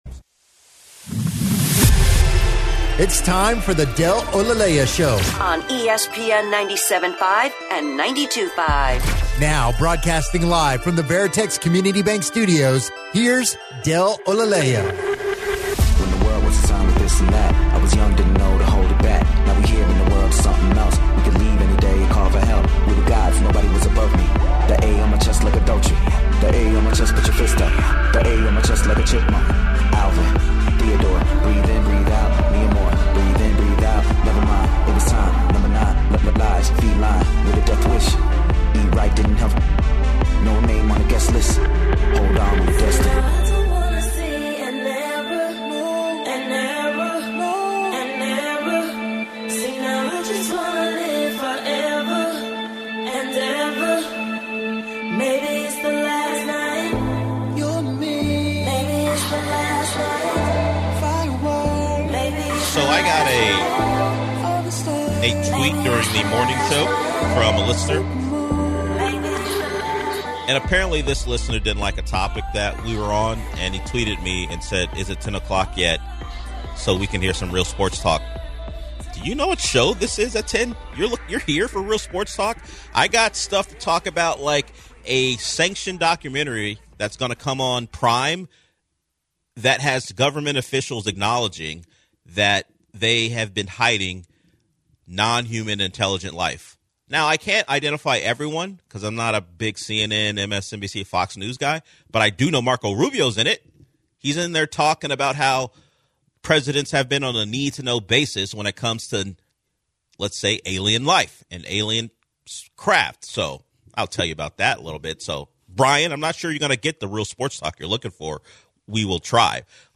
Caller talks new horror film Black Phone 2